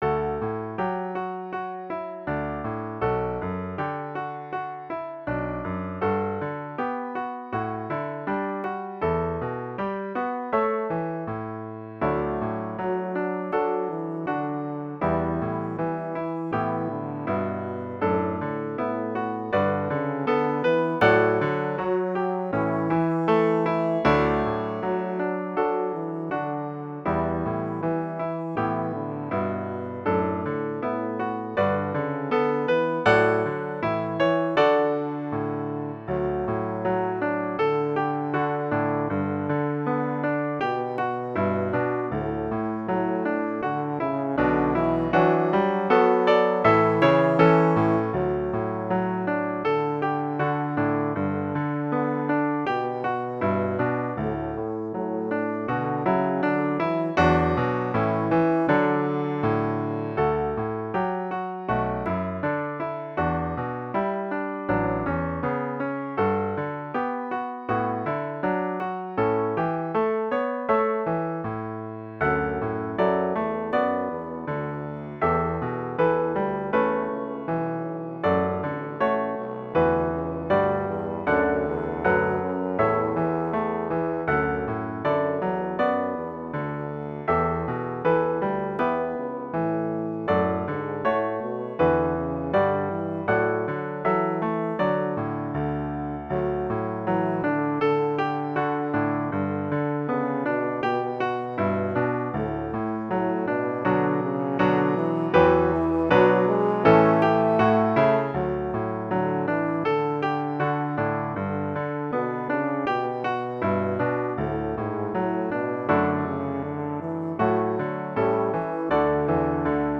Intermediate Instrumental Solo with Piano Accompaniment.
Christian, Gospel, Sacred.
a gentle, meditative mood.